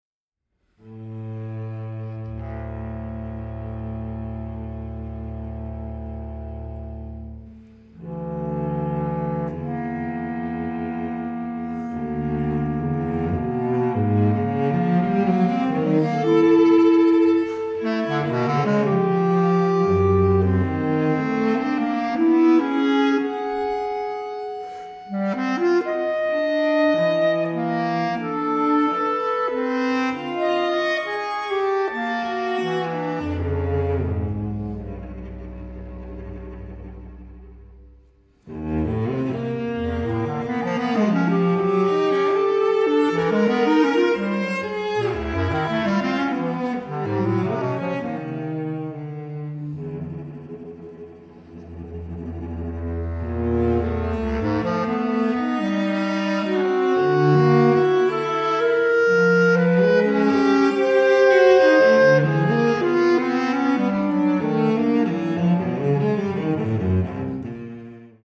chamber works for various instrumentations
bass clarinet